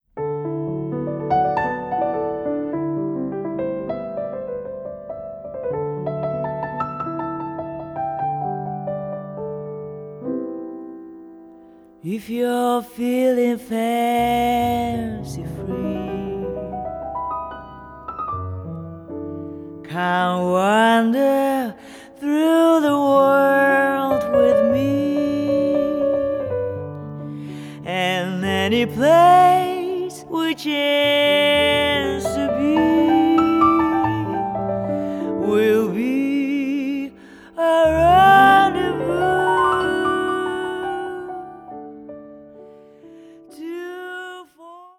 guitar
bass
harmonica
伸びやかなアルト・ボイス、確かな音程でキャリアを感じさせる深みのあるボーカルで魅了する